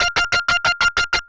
Synth 07.wav